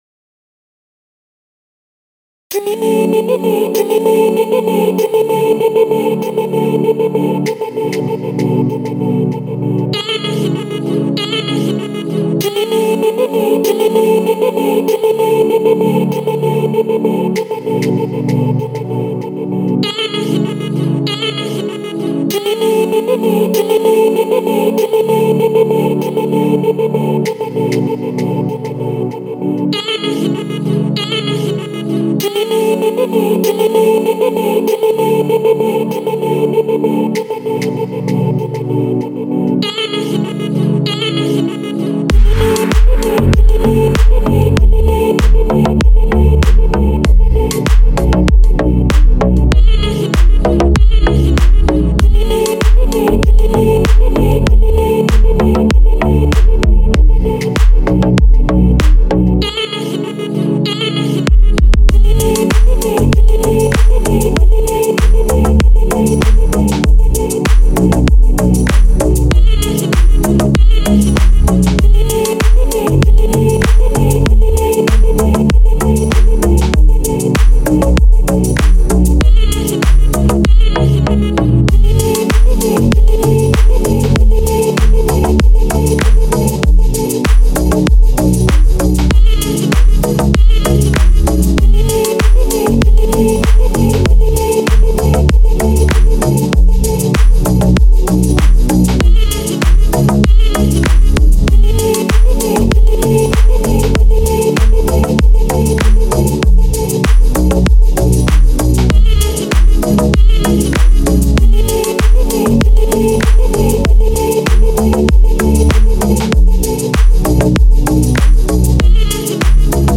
Deep House треки , Дип Хаус музыка